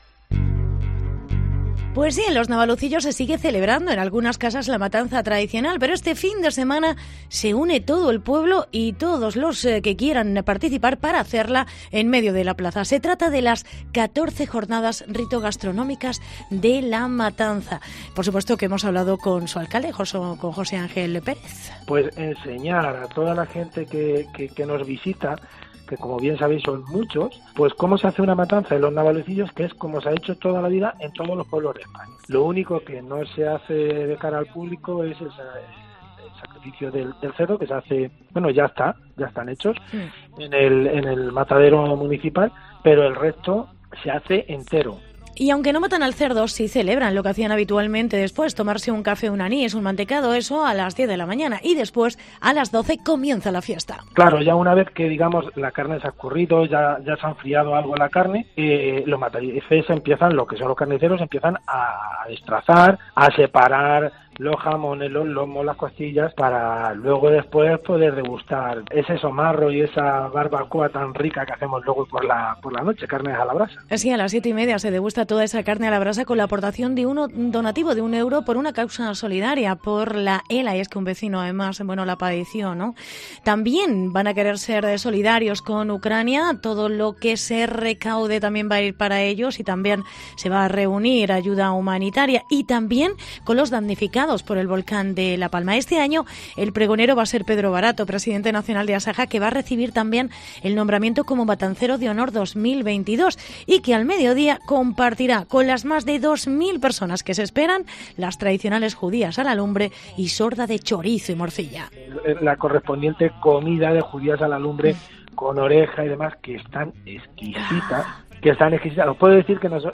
Entrevista José Angel Pérez, alcalde de los Navalucillos